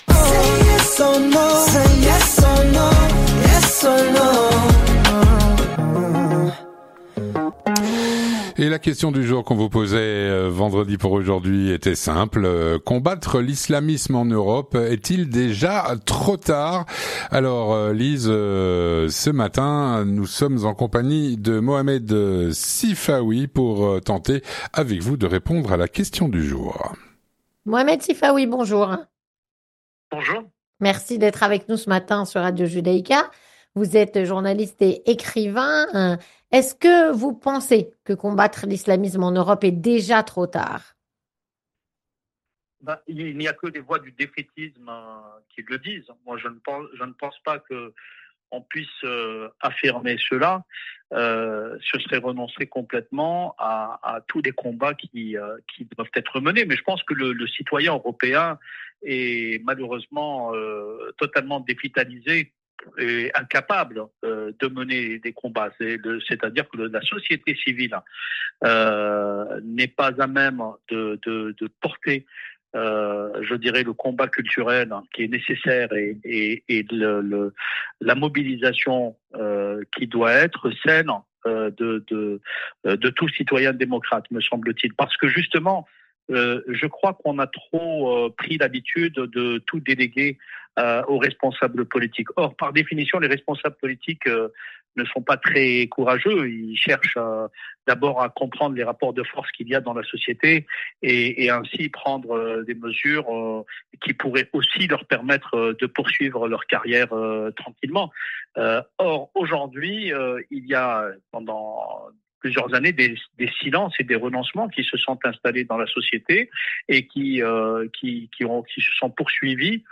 Mohamed Sifaoui, journaliste et écrivain, répond à "La Question Du Jour".